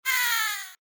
slowdown.mp3